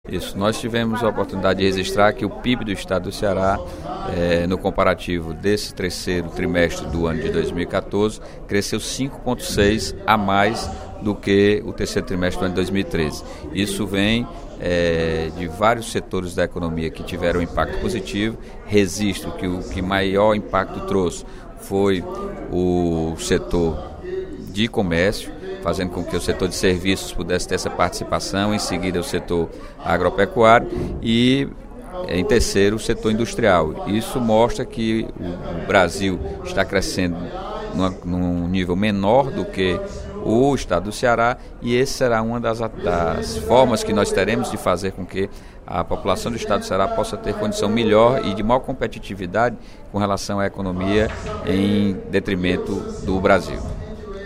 Durante o primeiro expediente da sessão plenária desta quarta-feira (10/12), o deputado Sérgio Aguiar (Pros) comentou números divulgados pelo Instituto de Pesquisa e Estratégia Econômica no Ceará (Ipece) sobre a economia do Ceará. O levantamento mostra que, por 18 meses consecutivos, o Estado mantém ritmo de crescimento maior que o brasileiro.
Em aparte, o deputado Roberto Mesquita (PV) enfatizou que o Ceará precisa crescer mais que a média nacional para poder se igualar aos estados que têm economia mais forte.